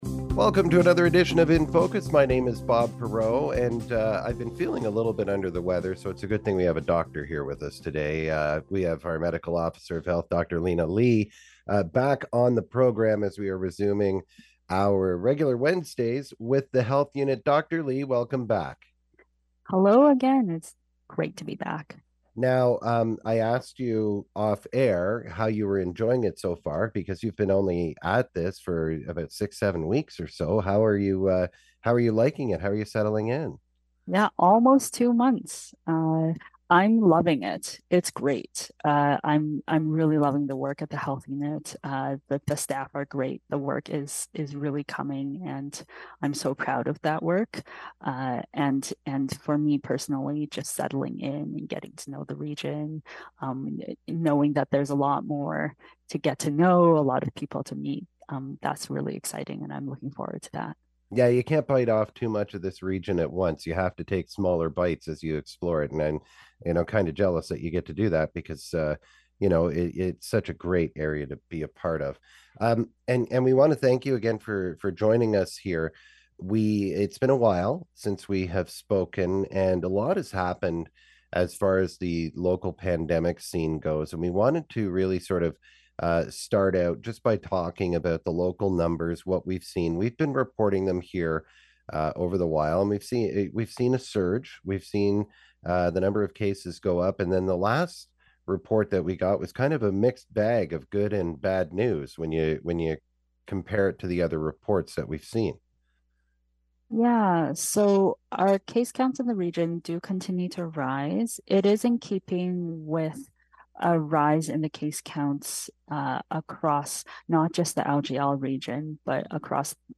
Our weekly chats with the health unit resume. Today we welcome Medical Officer of Health Dr. Linna Li with an update on the COVID-19 situation in Lanark, Leeds and Grenville.